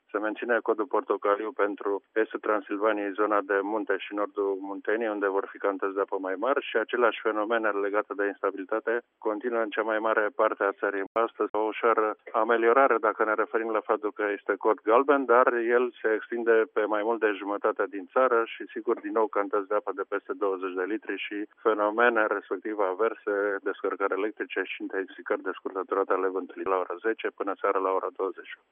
Sunt în continuare valabile mai multe avertizări de instabilitate atmosferică – cea mai severă – codul portocaliu – expiră la ora 10 dar se va extinde cel galben. Meteorologul